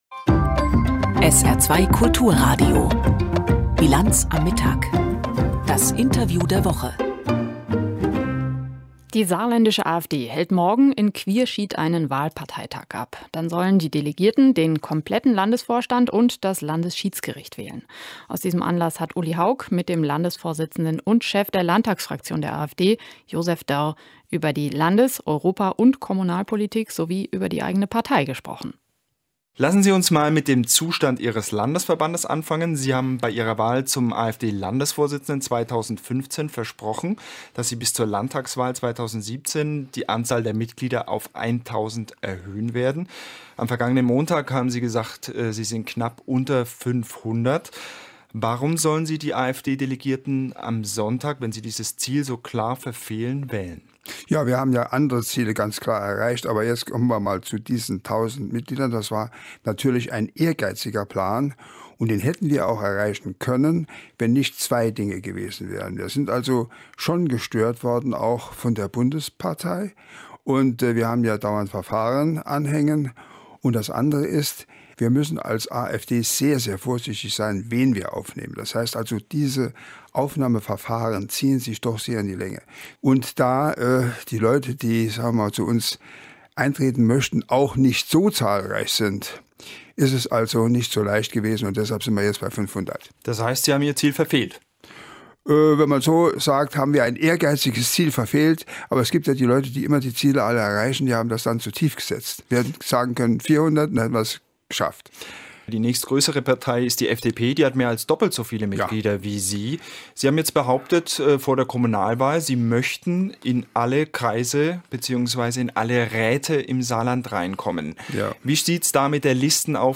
Interview SR2 mit Josef Dörr 02.Februar.2019